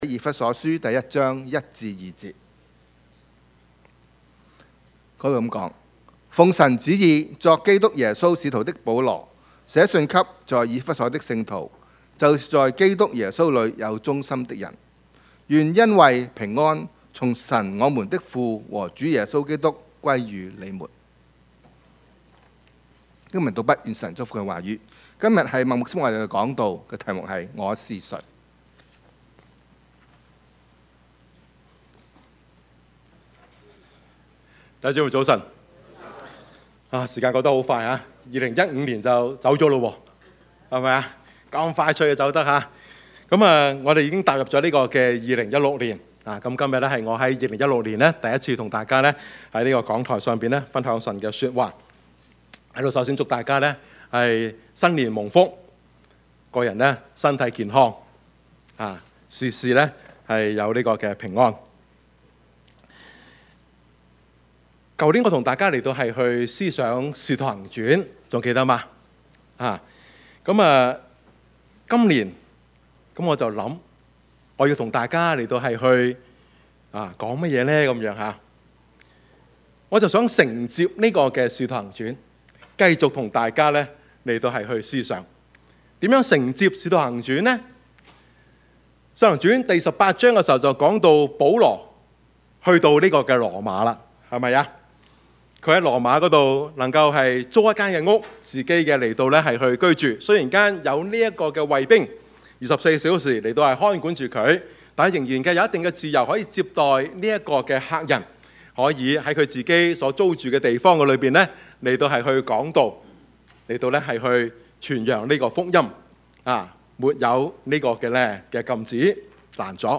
Service Type: 主日崇拜
Topics: 主日證道 « 仍是有望（福音主日） 高峰上的頌歌 »